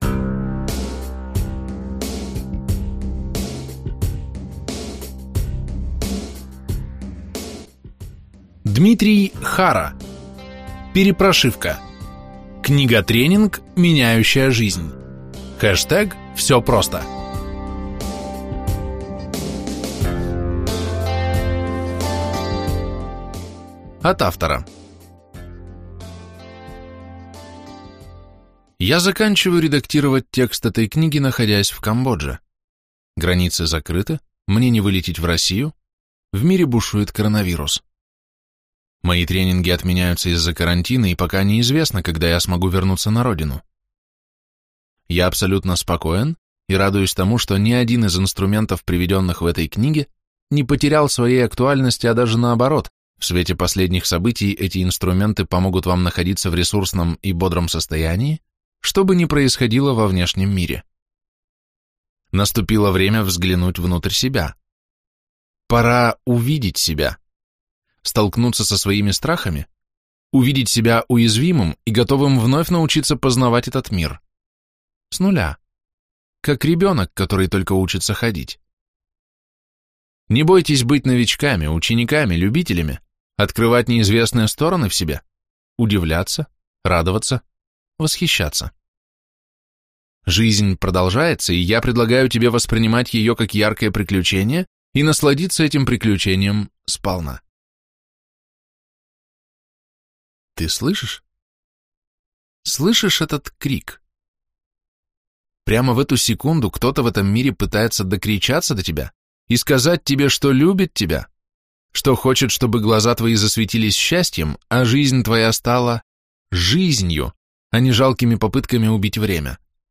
Аудиокнига ПерепроШивка. Книга-тренинг, меняющая жизнь.